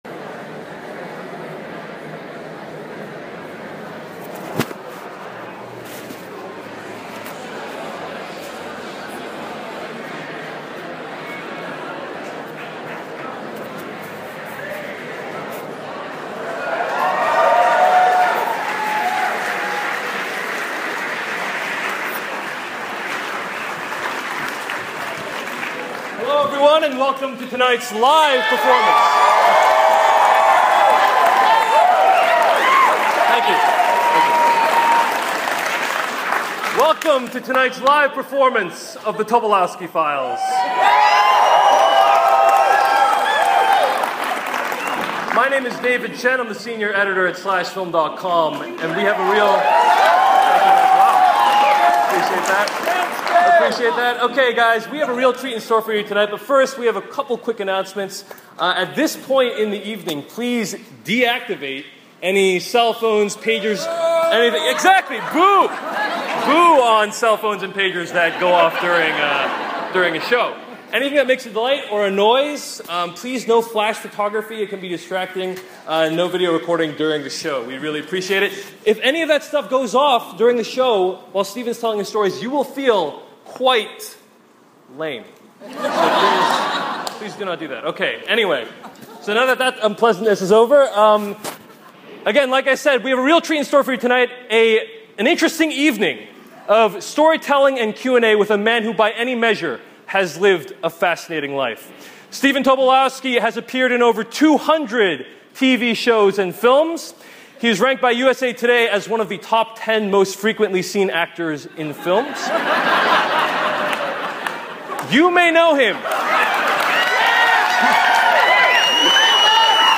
Last night's intro to the Tobolowsky show (audio evidence of the SCREAMING masses)